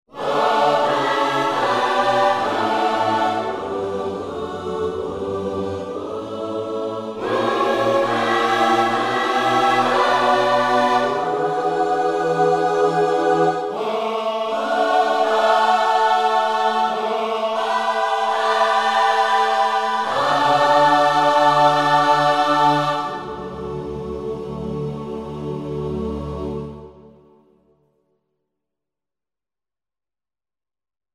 Gospel v_s 1 demo =1-A01.mp3